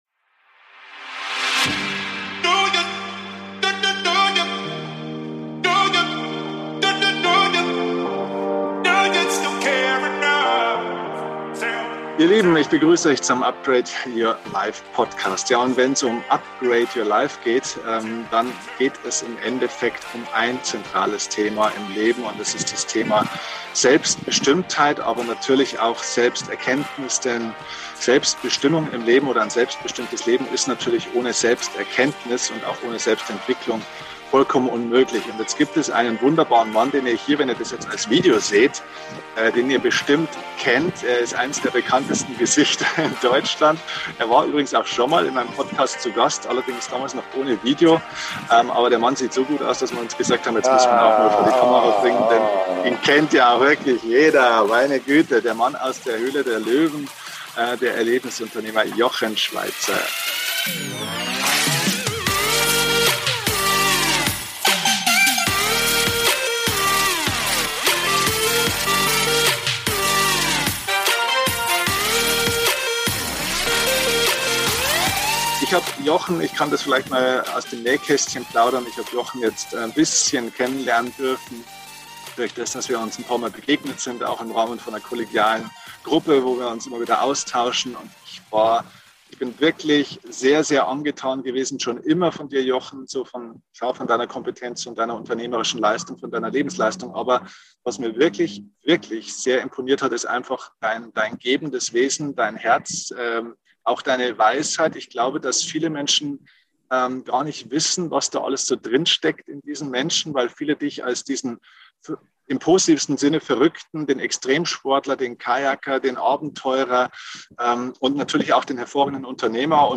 Beschreibung vor 4 Jahren #427 Die Begegnung – Gespräch mit Jochen Schweizer über ein selbstbestimmtes Leben Heute ist der Erfolgs-Unternehmer Jochen Schweizer zu Gast im UPGRADE YOUR LIFE Podcast. Wir haben uns mit der spannenden Frage nach einem selbstbestimmten Leben beschäftigt.